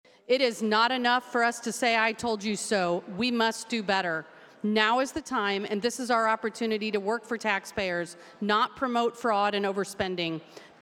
On the House floor, State Representative Amy Elik said the audit shows Republican concerns were valid.